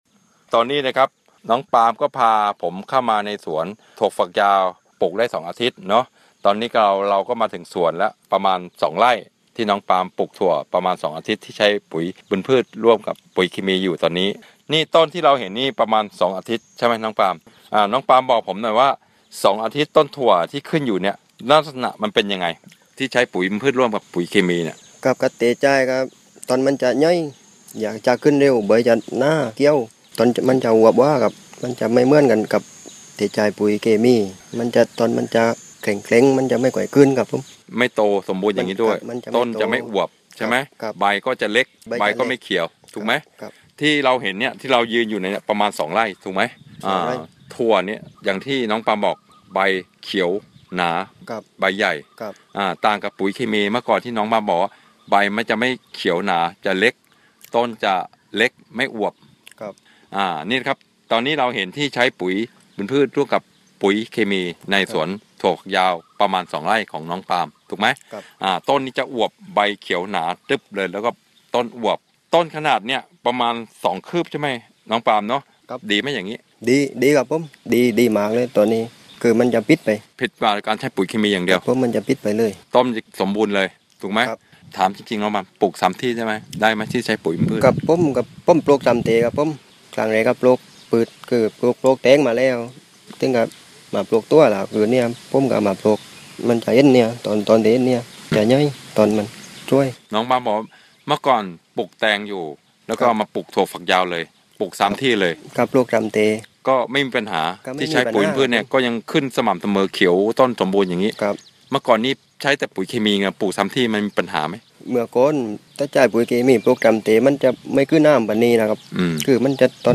เสียงสัมภาษณ์เกษตรกร